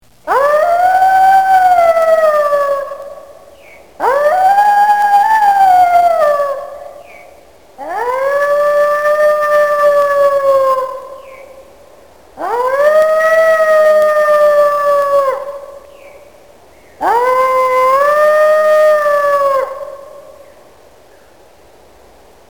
Звуки воя волка
Жуткий вой одинокого волка